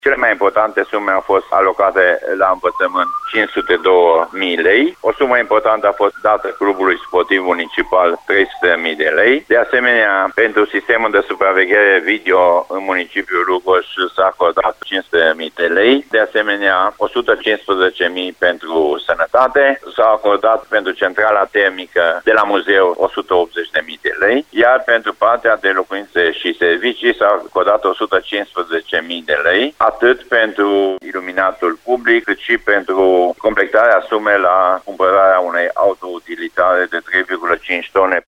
Cum au fost împărţiţi banii, explică primarul Lugojului, Francisc Boldea: Francisc Boldea